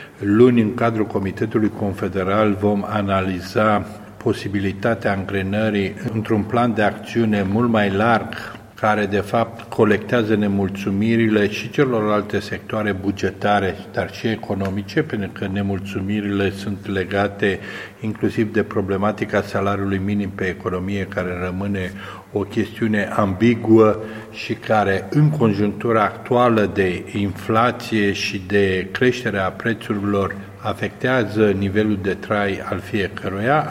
Problema a fost dezbătută astăzi la Tîrgu-Mureş, în cadrul ședinței Comitetului federal al Federaței Sindicatelor PRO ADMINISTRAȚIE din România, afiliată la CNS Cartel ALFA.